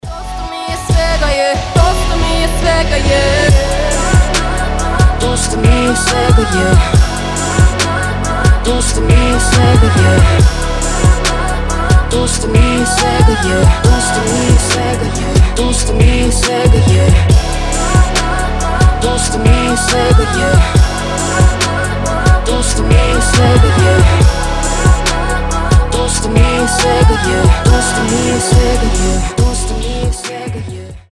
• Качество: 320, Stereo
атмосферные
женский голос